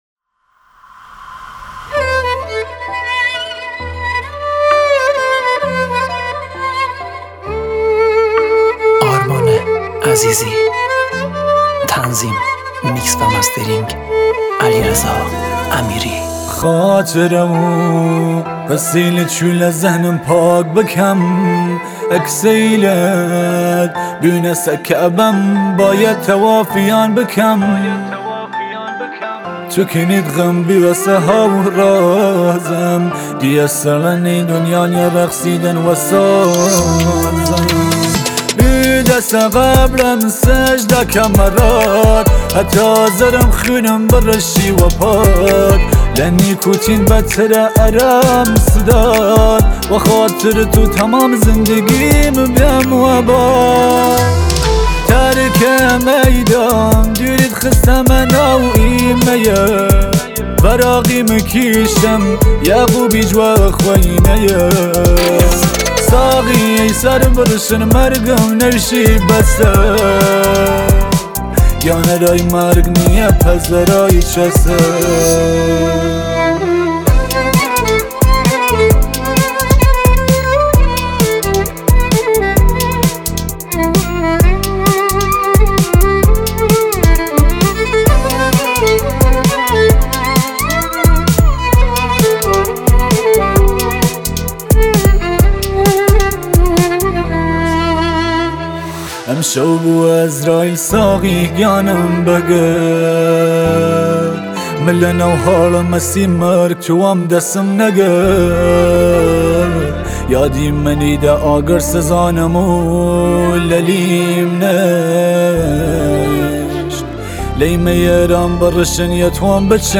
آهنگ ایرانی